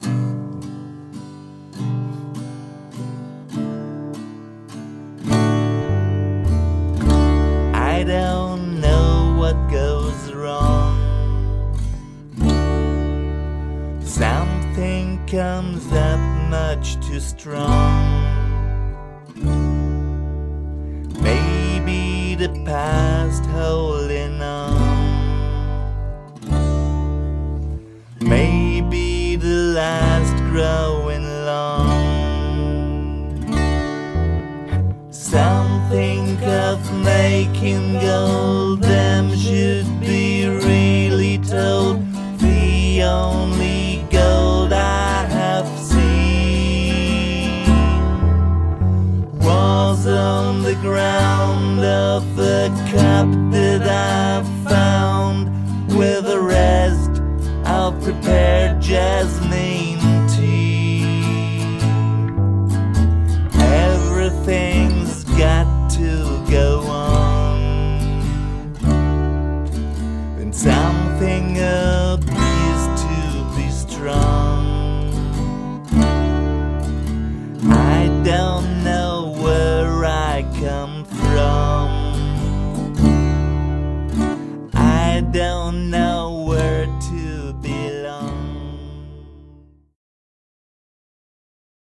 ld-voc., keyb.
voc., ac-g.
voc., bg.